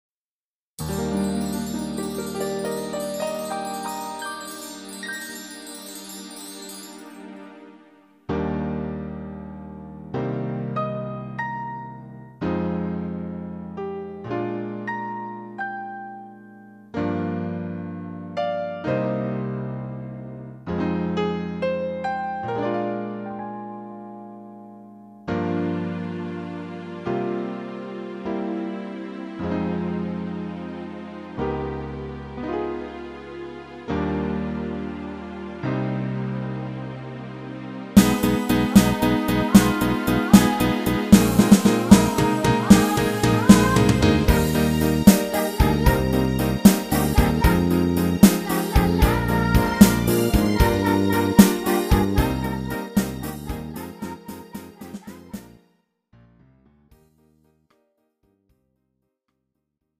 팝송) MR 반주입니다.